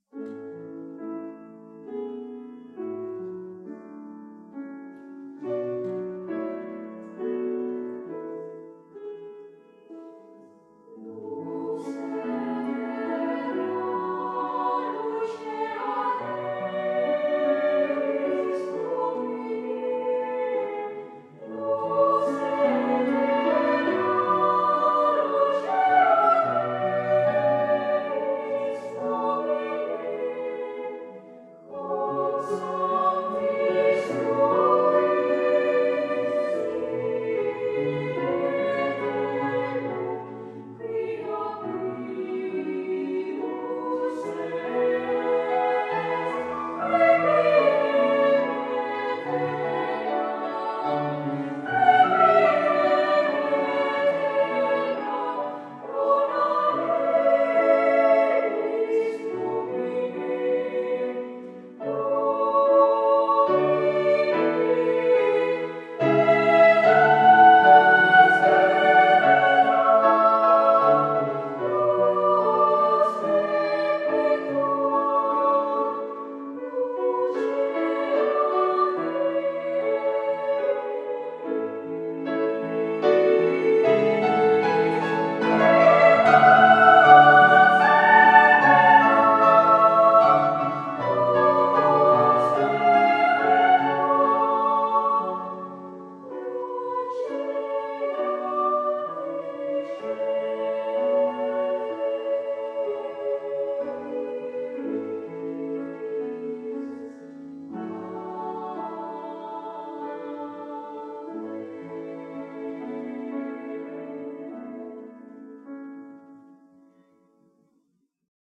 Voicing: SA
Instrumentation: piano or organ